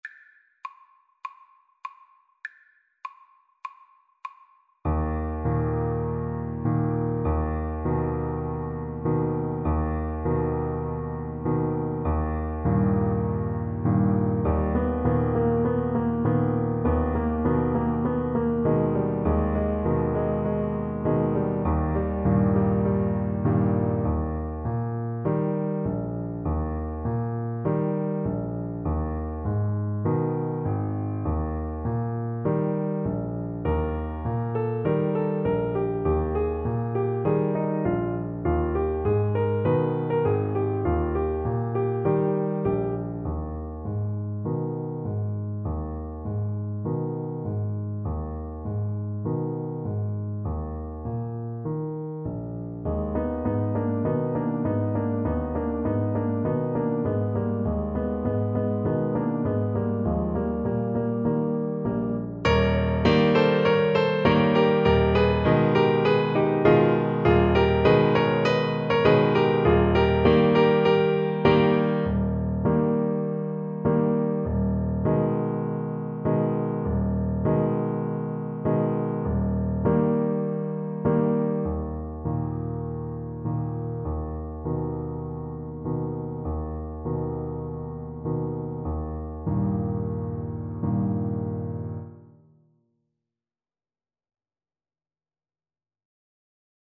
Clarinet
Eb major (Sounding Pitch) F major (Clarinet in Bb) (View more Eb major Music for Clarinet )
Moderato
4/4 (View more 4/4 Music)